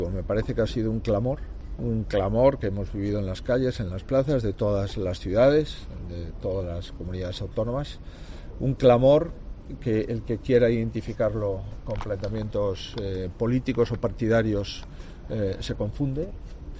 En declaraciones en Valencia tras participar en la reunión del grupo parlamentario del PP Europeo, ha añadido que dicho clamor fue el del 50 por ciento de la población española -las mujeres- y del que deben "tomar nota" los gobiernos locales, los autonómicos, el Ejecutivo central y las Cortes generales.